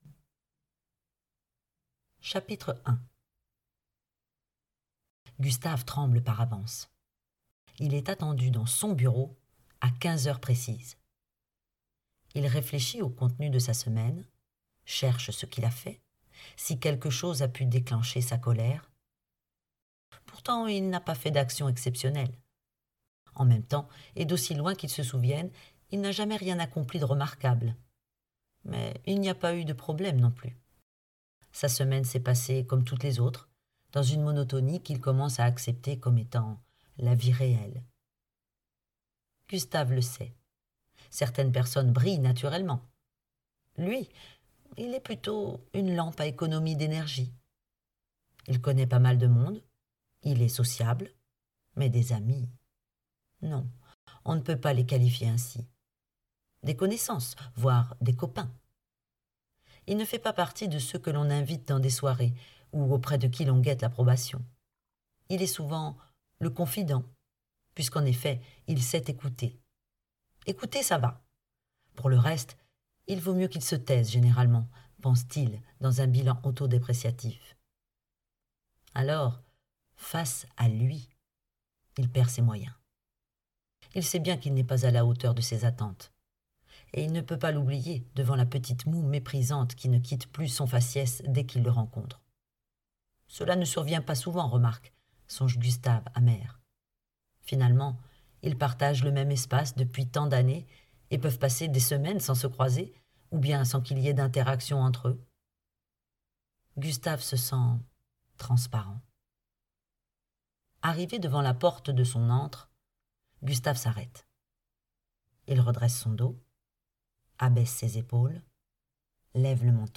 0% Extrait gratuit Disponible en ebook INTERNATO 5 , 99 € Internato de Céline Servat Éditeur : M+ Livre audio Paru le : 28/12/2020 Céline SERVAT INTERNATO Quand son père lui annonce qu'il part étudier en Argentine, Gustave, dix-sept ans, pense partir en voyage linguistique.